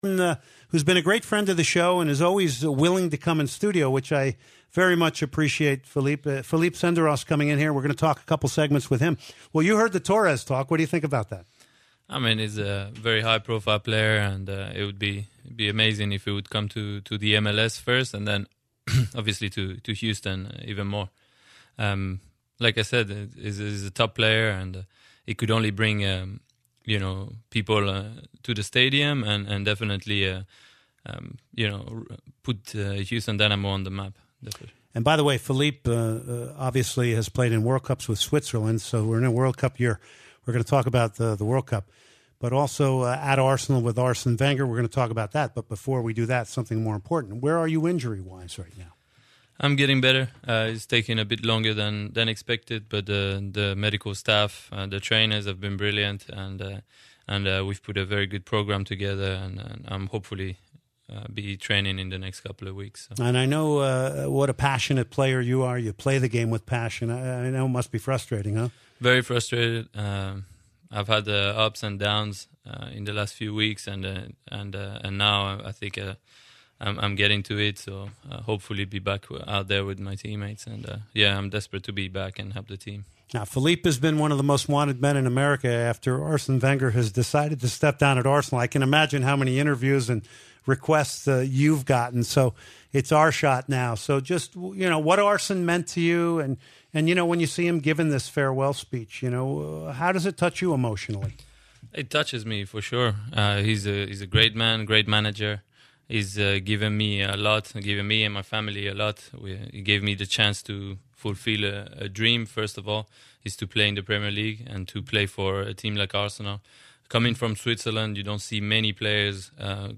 05/09/2018 Soccer Matters Bonus: Philippe Senderos Interview
Philippe Senderos is in studio to join the show. The Swiss defender has joined the Dynamo to help the team.